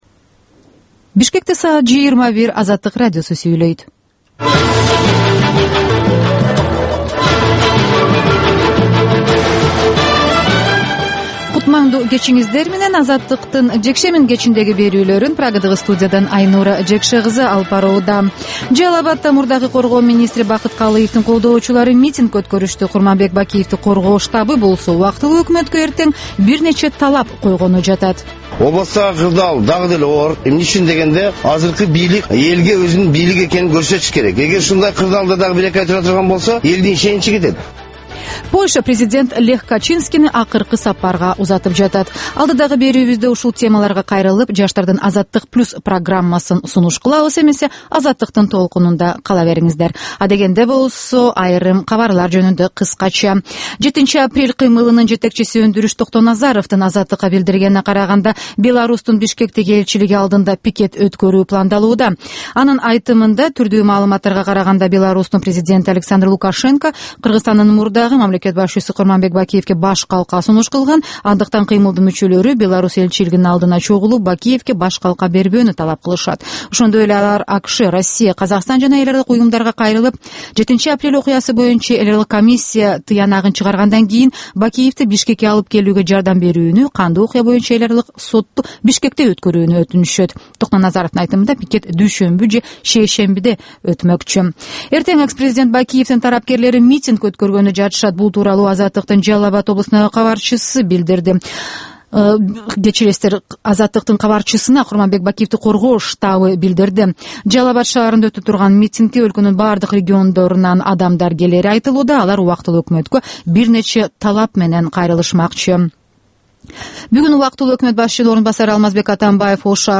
Бул кечки үналгы берүү жергиликтүү жана эл аралык кабарлардан, репортаж, маек, баян жана башка берүүлөрдөн турат. "Азаттык үналгысынын" бул кечки берүүсү ар күнү Бишкек убактысы боюнча саат 21:00ден 21:30га чейин обого түз чыгат.